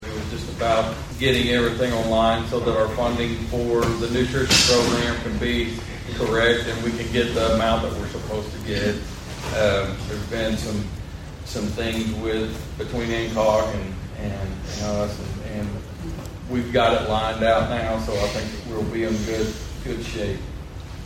The Board of Osage County Commissioners held a regularly scheduled meeting at the fairgrounds on Monday morning.
District Two Commissioner Steve Talburt gives